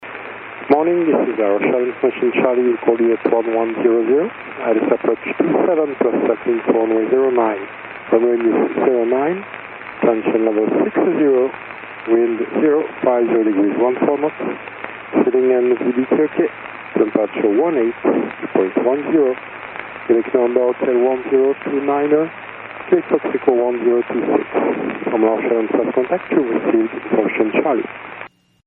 2018-lfbh-atis.mp3